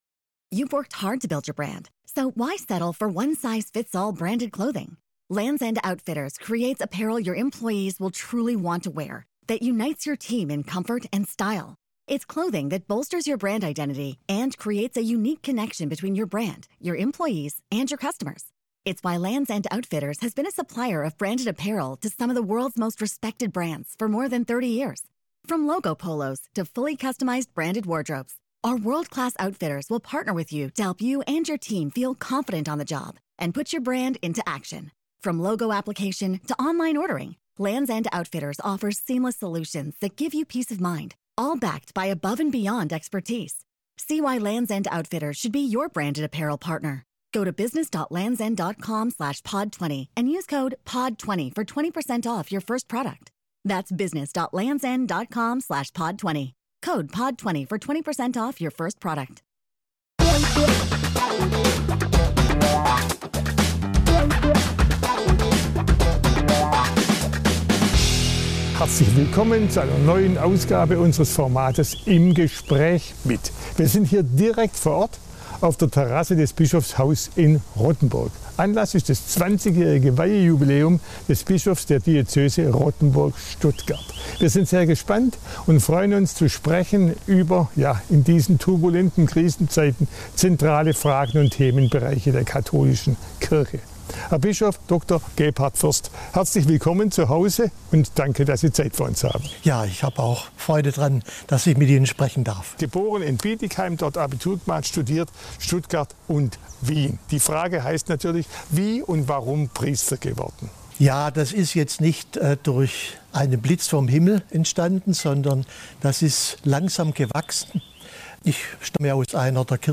Wir sprechen mit ihm darüber, wie der Berufswunsch Priester entstand, über die Coronakrise, Frauen in der Kirche, den Missbrauchsskandal in der katholischen Kirche und warum ihn die zunehmende Individualisierung der Gesellschaft besorgt.